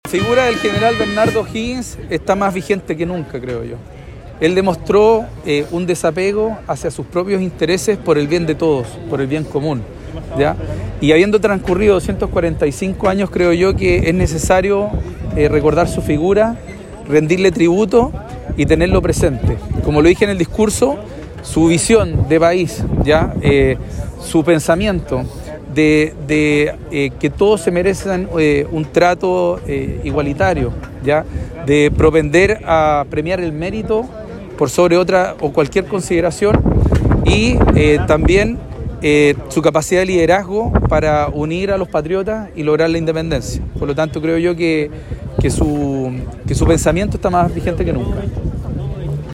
Con un acto realizado en Plaza de Armas, que consideró la colocación de ofrendas, Osorno conmemoró el natalicio número 245 del Libertador Bernardo O’Higgins Riquelme, que ocupa un sitial importante en nuestra historia, al participar en la gesta emancipadora para la independencia de Chile.